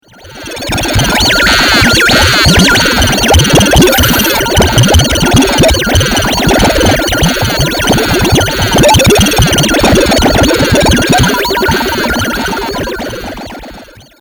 musique informatique